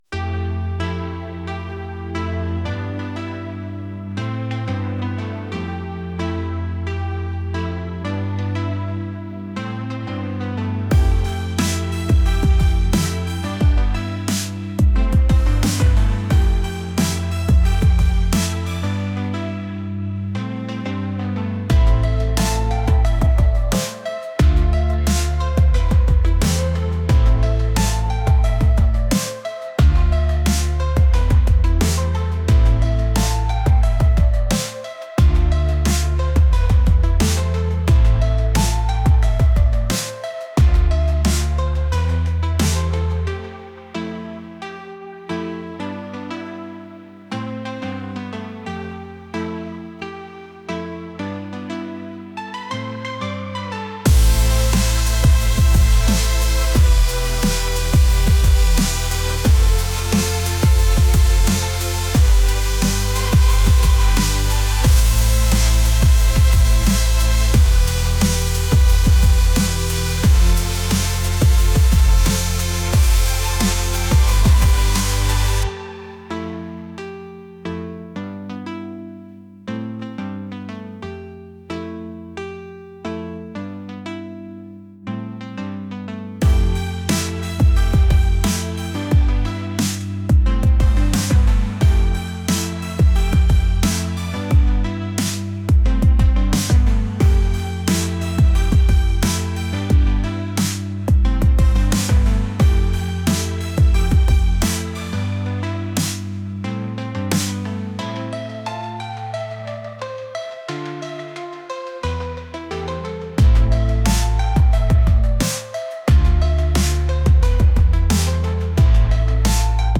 electronic | pop | ambient